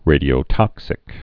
(rādē-ō-tŏksĭk)